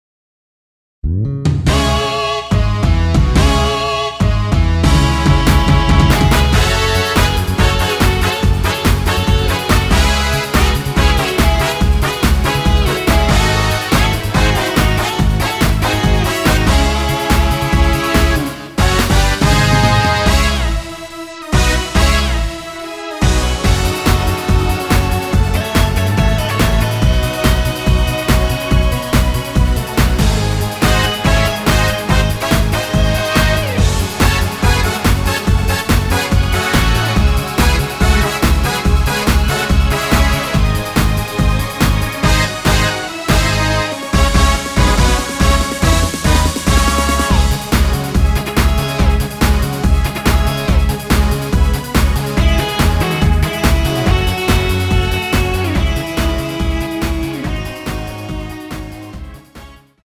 멜로디 포함된 MR 입니다.
C#m
앞부분30초, 뒷부분30초씩 편집해서 올려 드리고 있습니다.
중간에 음이 끈어지고 다시 나오는 이유는